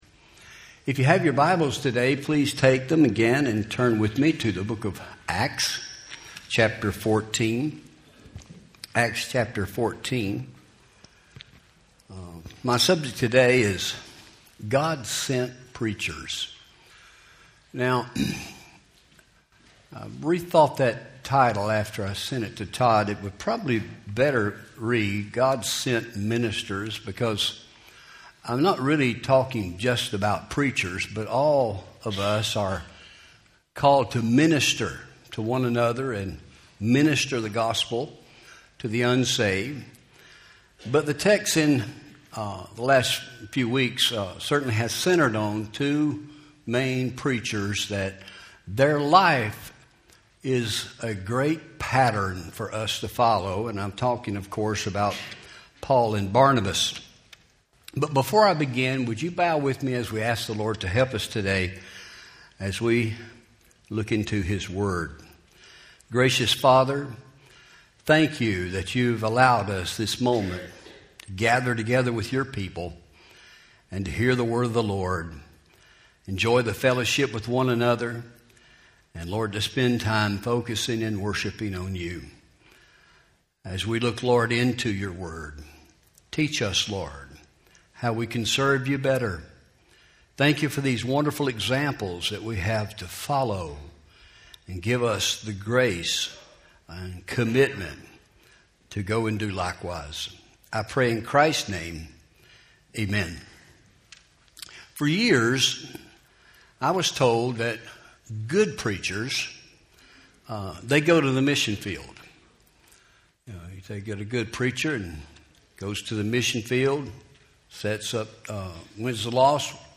Home › Sermons › God Sent Ministers